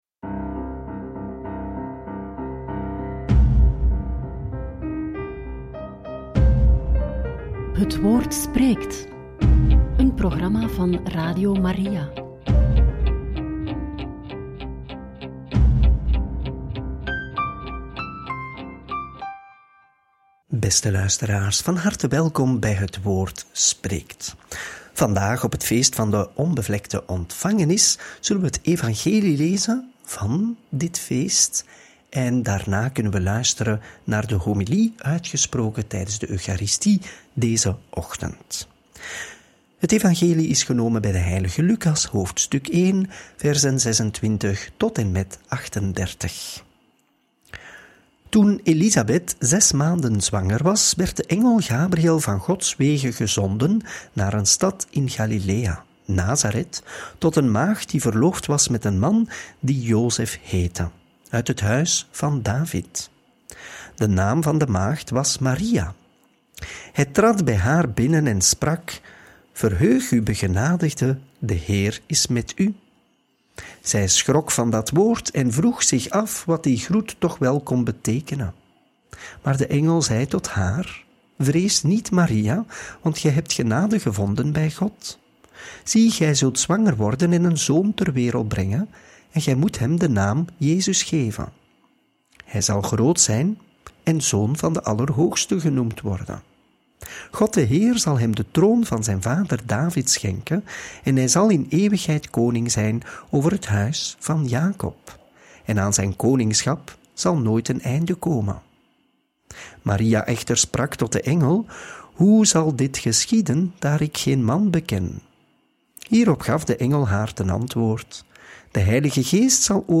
Homilie op het feest van de Onbevlekte Ontvangenis van de heilige maagd Maria – Radio Maria
homilie-op-het-feest-van-de-onbevlekte-ontvangenis-van-de-heilige-maagd-maria.mp3